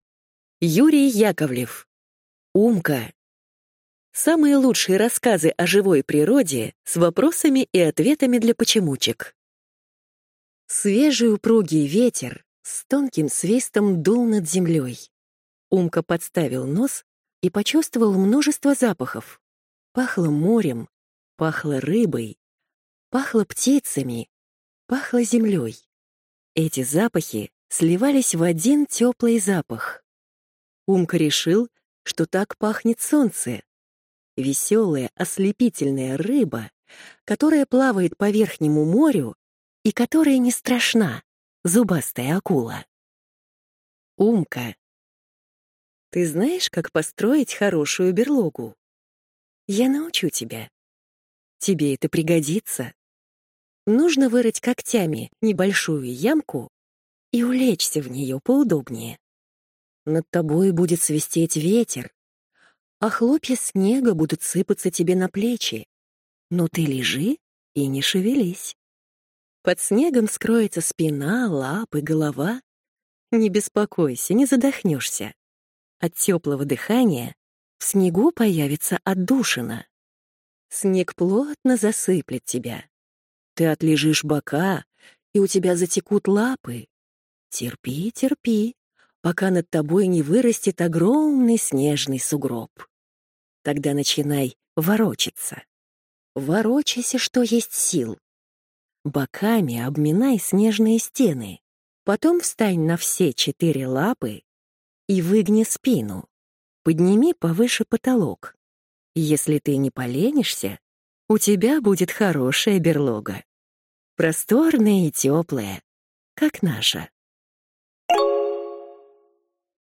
Аудиокнига Умка | Библиотека аудиокниг
Прослушать и бесплатно скачать фрагмент аудиокниги